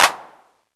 Index of /90_sSampleCDs/Classic_Chicago_House/Drum kits/kit01
cch_04_clap_mid_classic_remem.wav